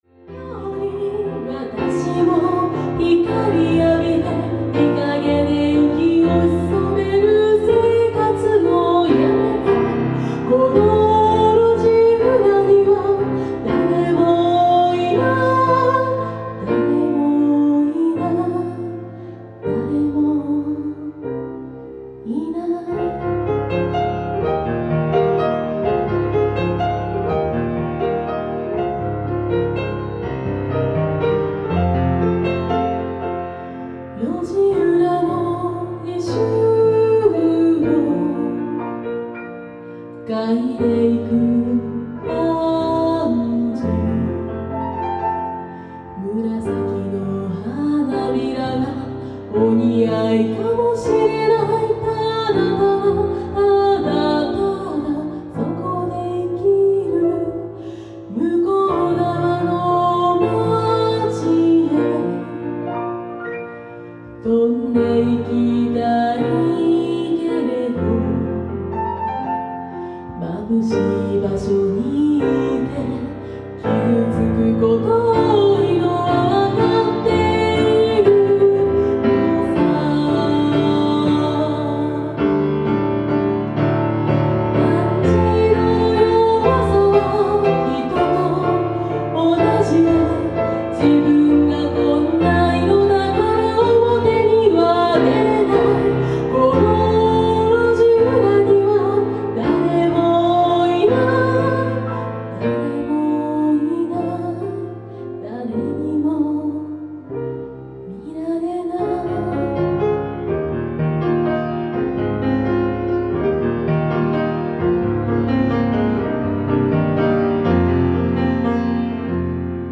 ピアノ
※１２年前のスタジオで録った音源ですので